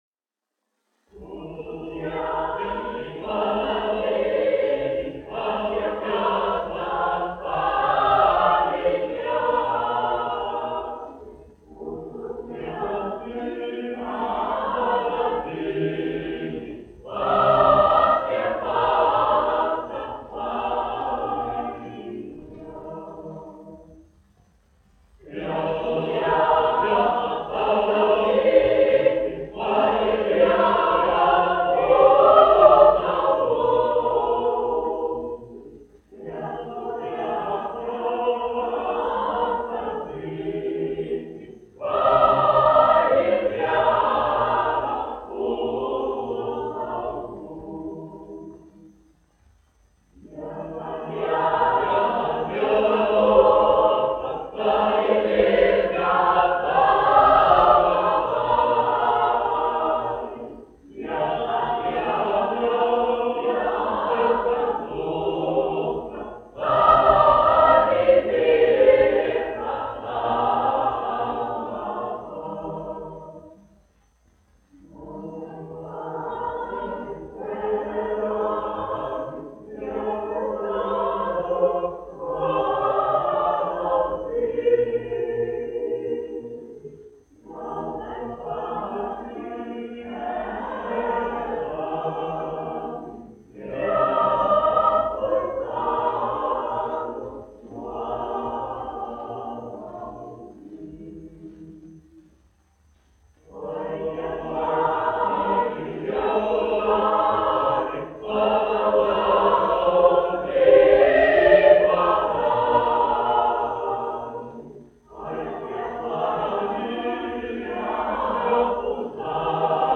Andrejs Jurjāns, 1856-1922, aranžētājs
Latvijas Nacionālā opera. Koris, izpildītājs
1 skpl. : analogs, 78 apgr/min, mono ; 25 cm
Latviešu tautasdziesmas
Kori (jauktie)
Skaņuplate